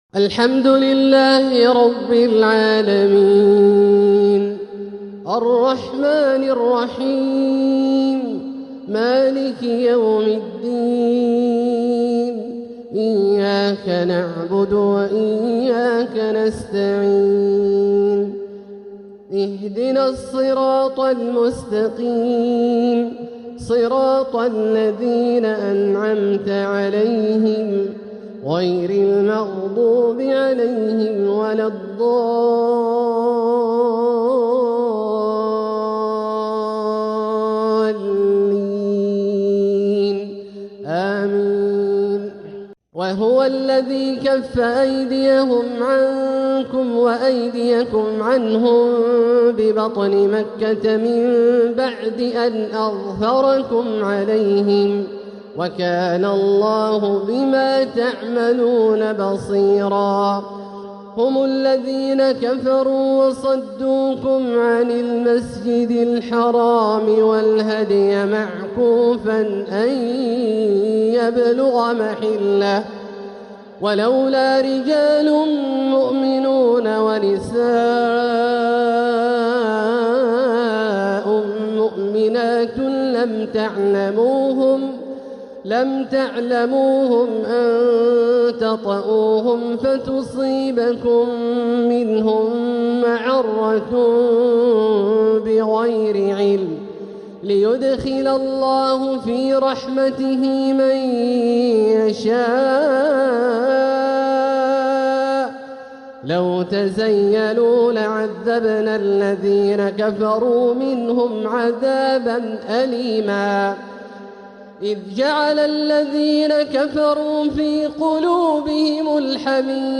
روائع المغرب | تلاوات رائعة حبرها المتميز د. عبدالله الجهني | فترة جمادى الأولى 1447هـ > إصدارات منوعة > المزيد - تلاوات عبدالله الجهني